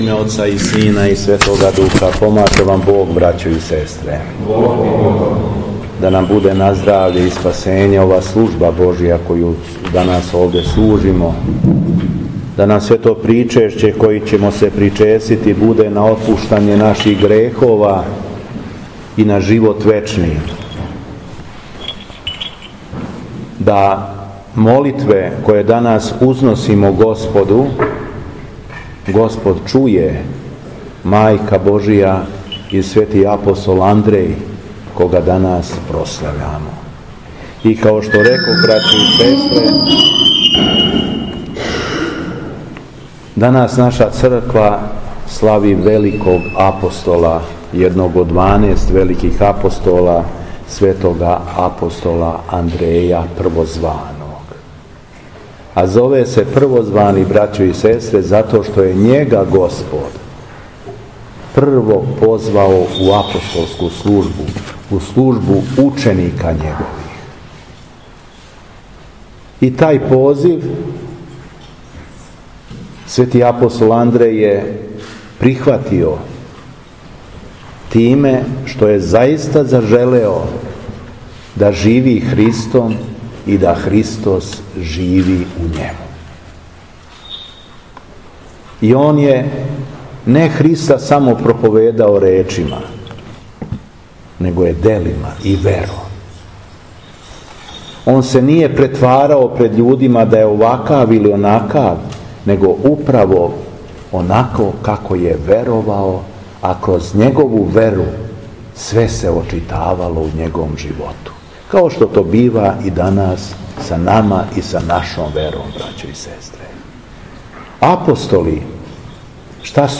СВЕТА АРХИЈЕРЕЈСКА ЛИТУРГИЈА У ХРАМУ ПРЕСВЕТЕ БОГОРОДИЦЕ ТРОЈЕРУЧИЦЕ У МАЛИМ ПЧЕЛИЦАМА - Епархија Шумадијска
Беседа Епископа шумадијског Г. Јована